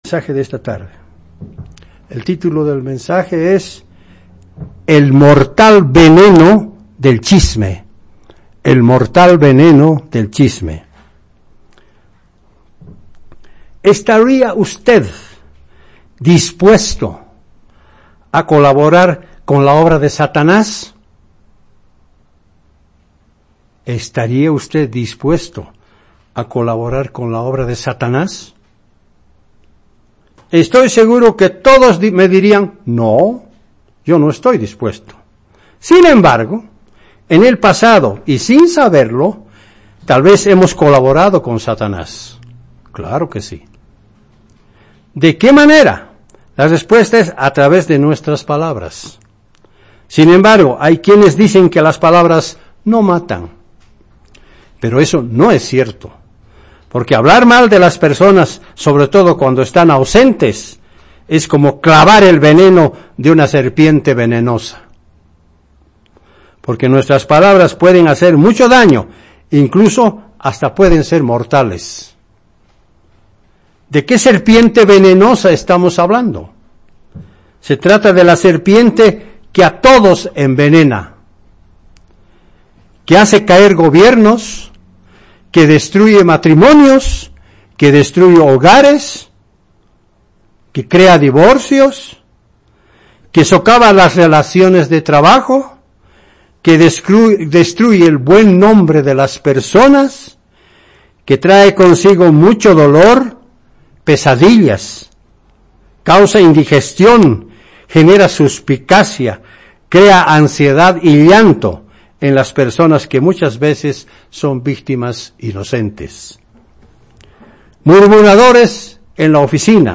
Given in La Paz